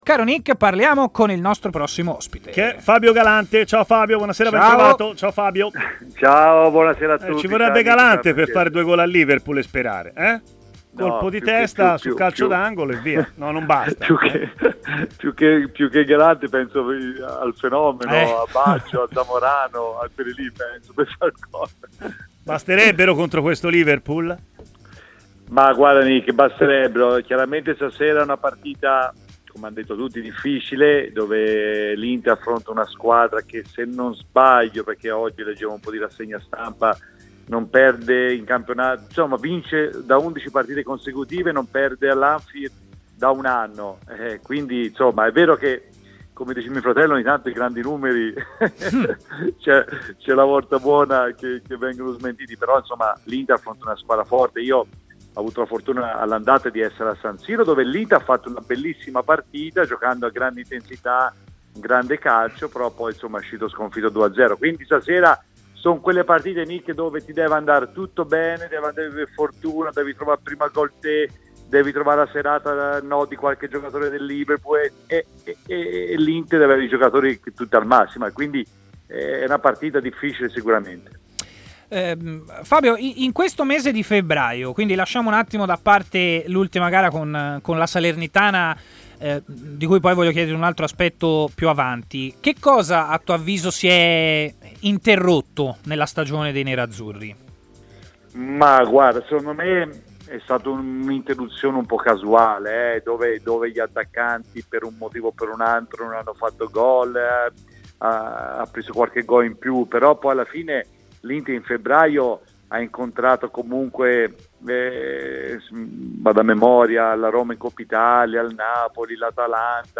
L'ex difensore Fabio Galante ha parlato in diretta su TMW Radio, durante la trasmissione Stadio Aperto